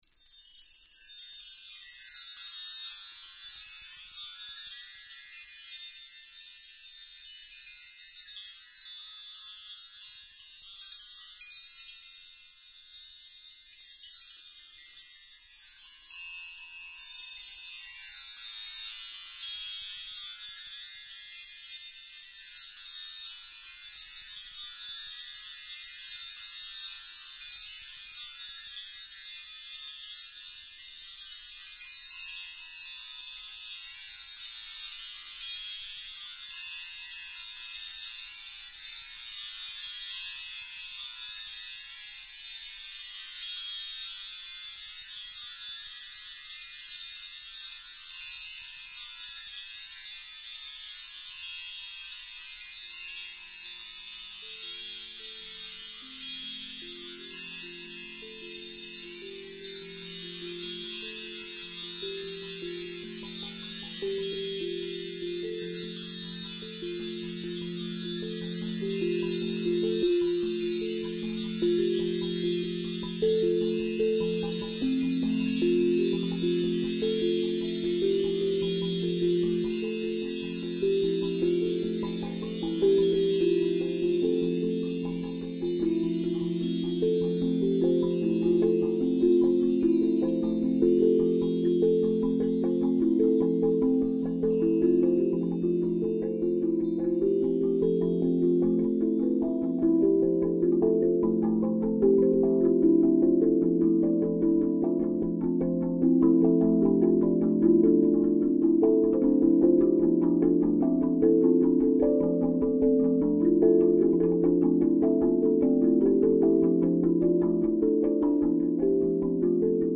But he also had a number of bells and chimes: wind chimes, sleigh bells, bell trees, dinner chimes, temple bells, etc. that he kept.
I had wanted to do a piece using many of these, augmented by several metallophones (kalimba, tongue drum) that I had collected myself. metals is that piece. I think I had also gotten a little 'over-synthesized' lately, and I wanted to make some music that had no analog or digital synthetic character. I done played them bells meself! I added some reverb at one point in this piece, but it's pretty much the whole "natural" shebang.